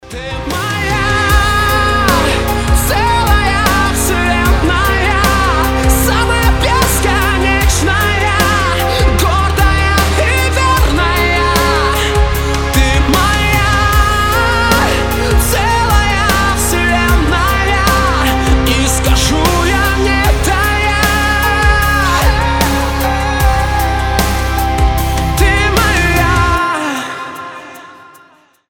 • Качество: 320, Stereo
громкие